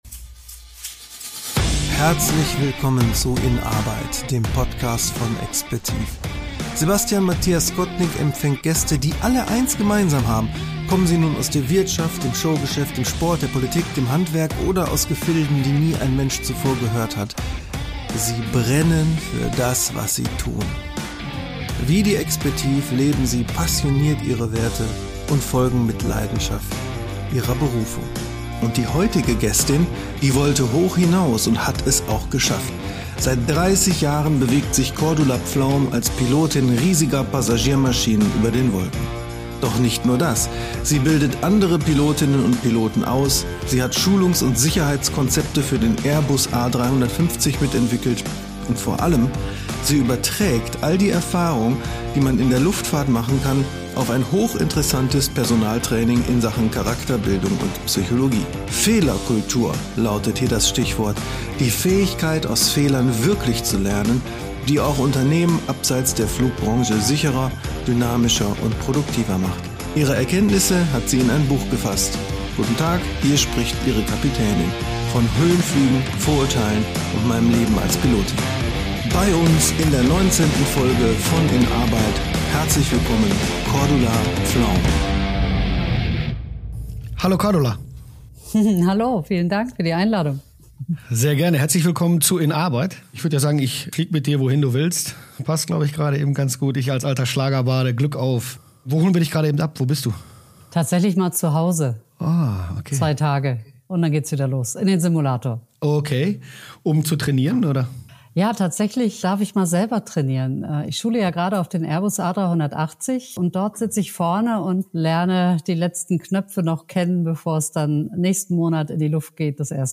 Ein Gespräch über die ersten Frauen in einer Männerbranche, über die Instrumente im Cockpit und die Kunst, mit klaren Rollen ein Team zu führen, ohne auch bei hoher Hierarchie unnötig abzuheben.